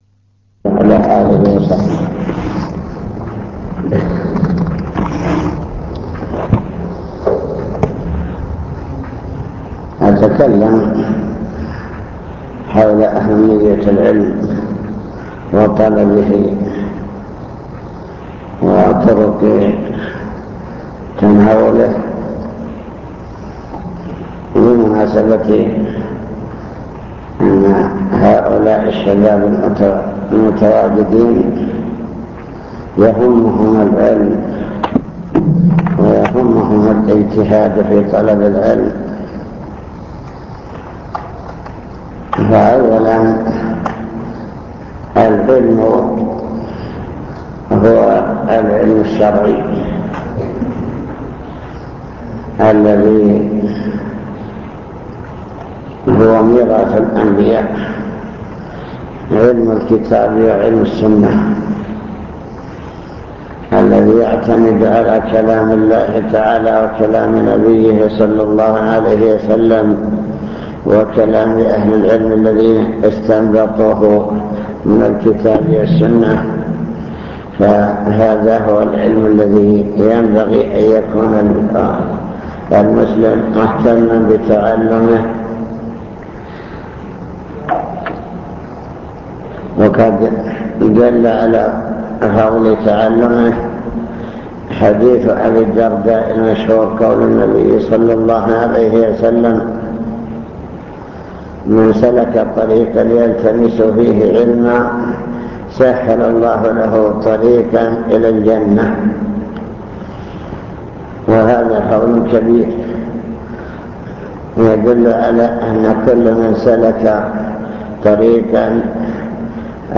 المكتبة الصوتية  تسجيلات - لقاءات  كلمة حول طلب العلم